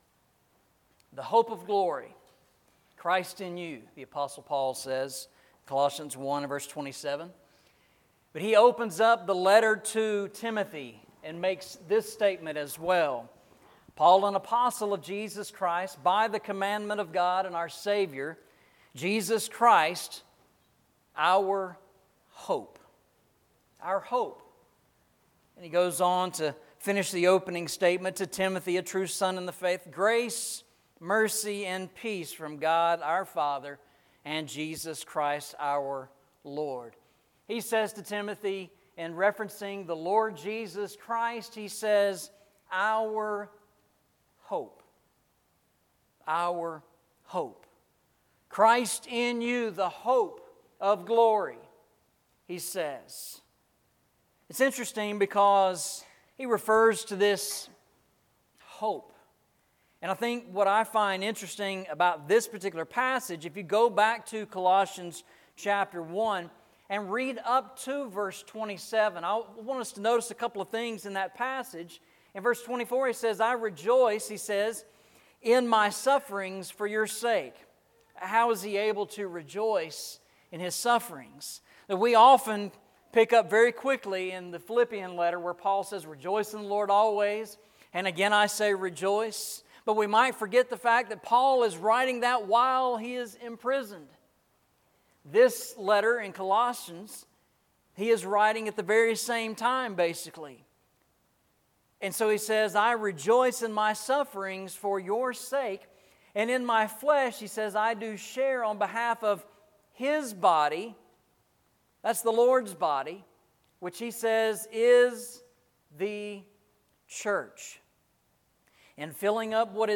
Eastside Sermons Passage: Colossians 1:27 Service Type: Sunday Morning « Walking Through the Bible